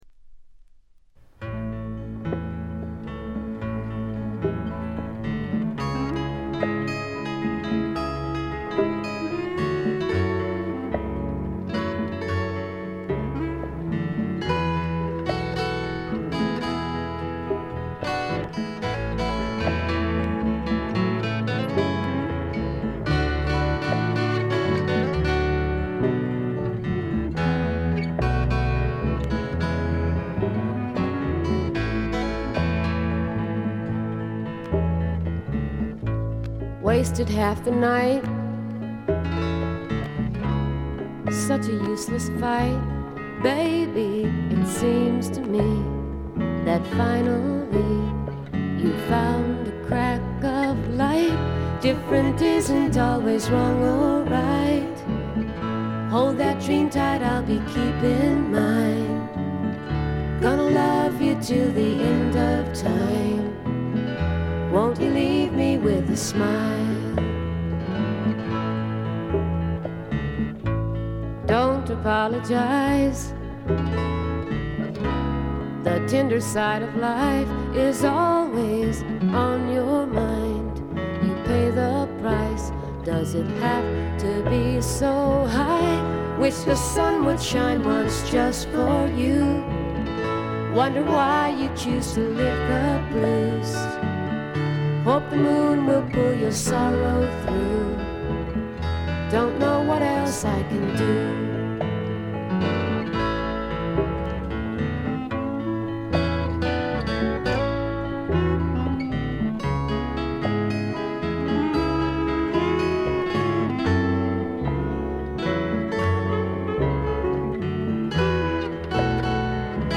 軽微なチリプチ少々。
フォーキーな女性シンガーソングライター作品の大名盤です！
試聴曲は現品からの取り込み音源です。